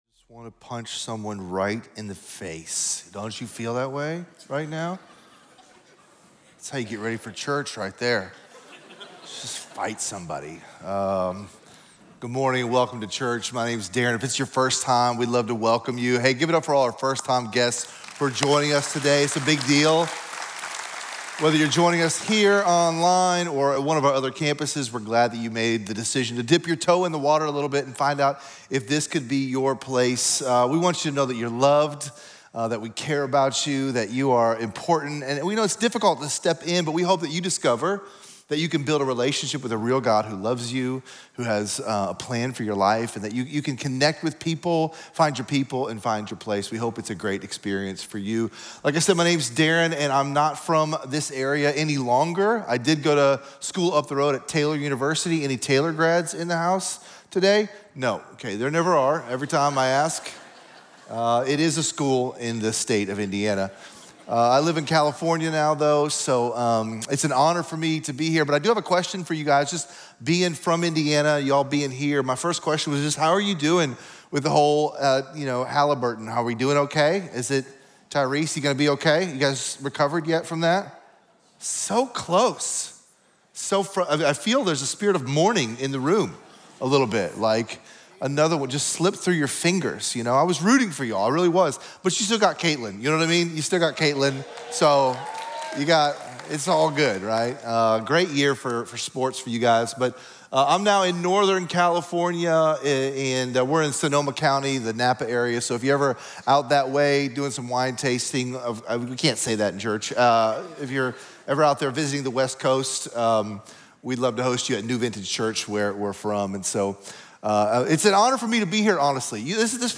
Whether your marriage is strong or struggling, this talk will help you fight the right battles.